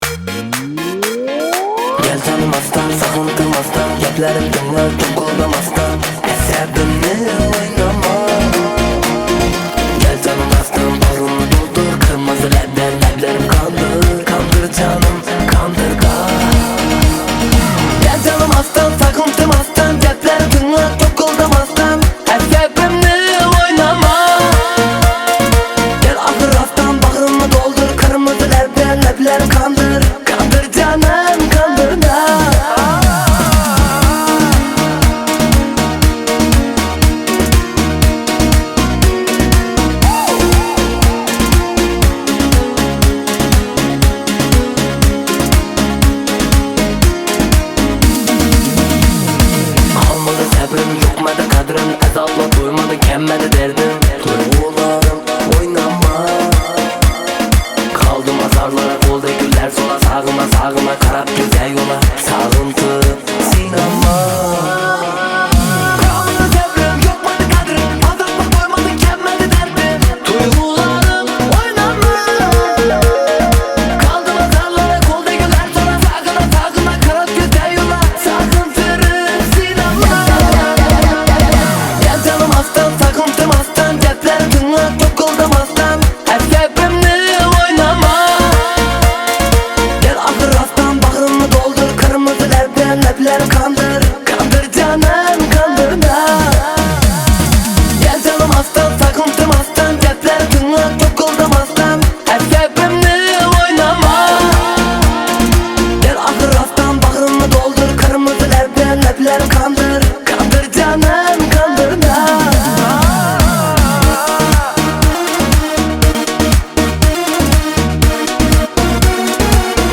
Жанр: Турецкие